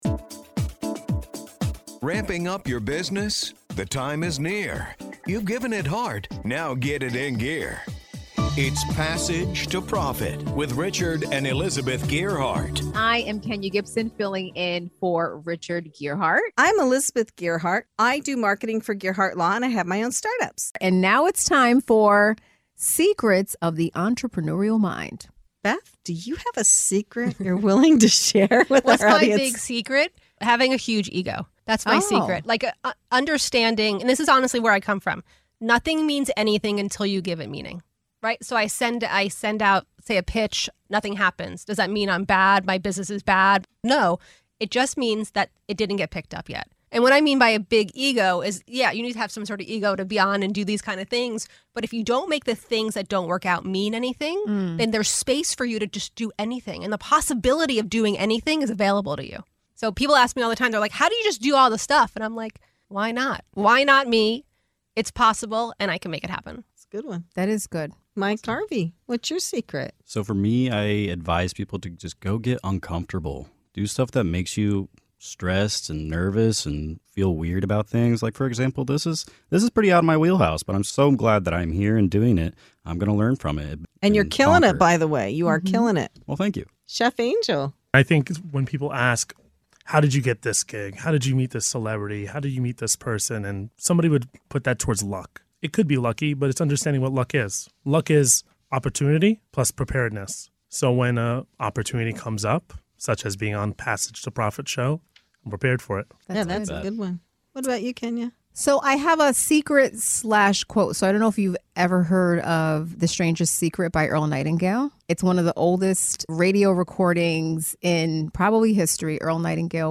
In this segment of “Secrets of the Entrepreneurial Mind” on the Passage to Profit Show, hear insights from bold risk-takers and seasoned pros. From harnessing a strong sense of self-belief to getting comfortable with discomfort, our guests share powerful strategies for seizing opportunities, redefining "luck," and manifesting success.